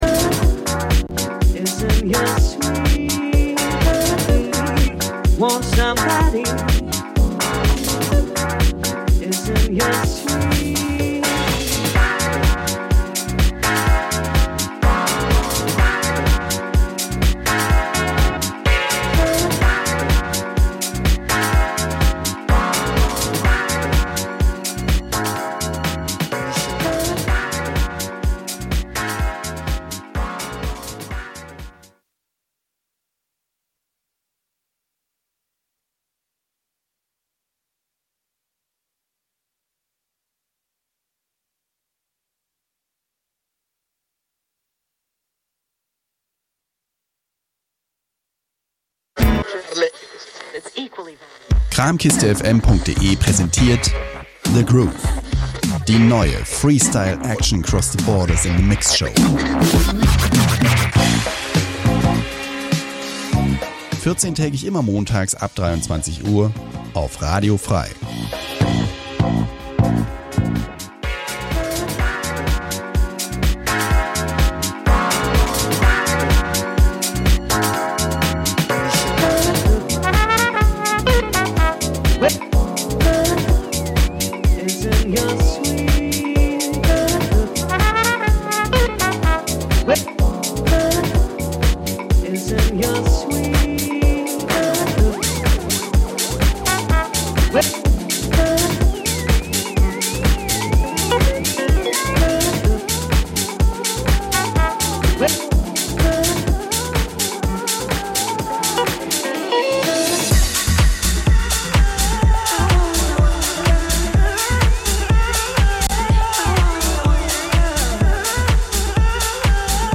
Experimental, Dub, Electronica, etc The Groove l�uft jeden zweiten Montag 23-01 Uhr und wird wechselnd am 1.
House, Drum�n�Bass, Breaks, Hip Hop, ...